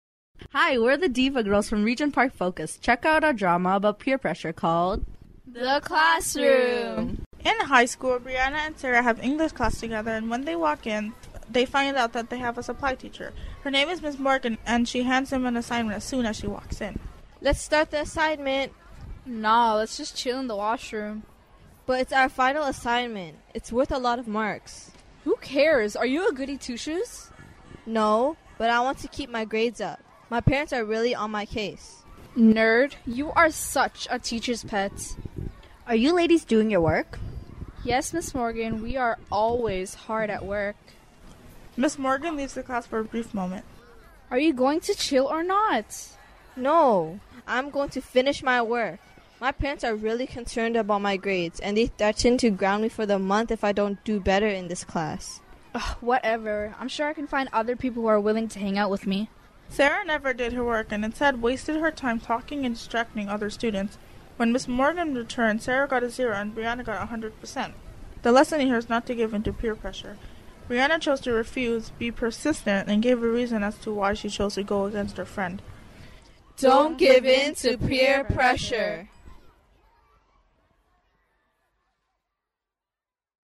The following are radio works that were scripted and produced by young people involved in the Diva's Young Women's Media Program.
The Classroom An audio dramatization about peer pressure.